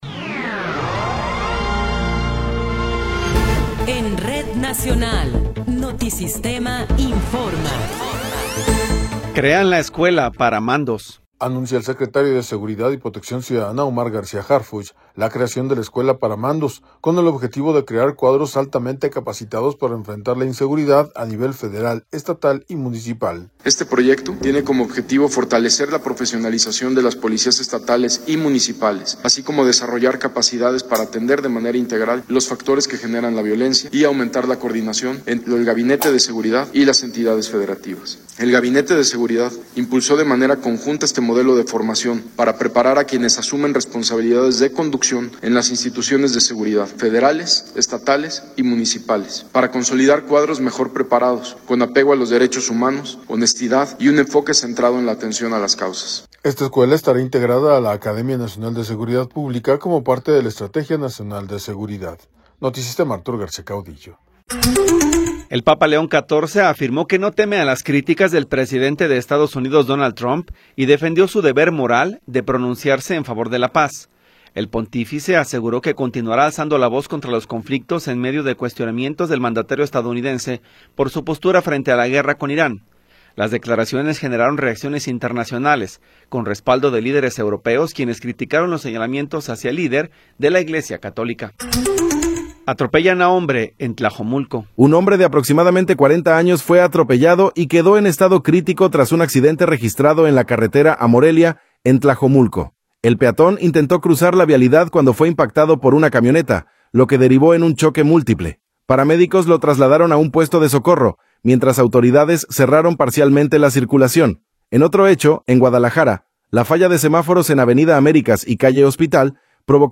Noticiero 11 hrs. – 14 de Abril de 2026